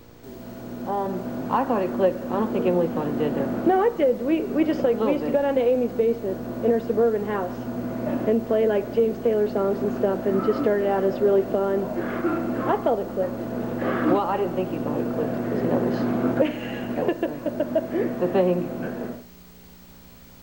13. interview (0:20)